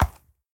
Minecraft Version Minecraft Version snapshot Latest Release | Latest Snapshot snapshot / assets / minecraft / sounds / mob / horse / soft5.ogg Compare With Compare With Latest Release | Latest Snapshot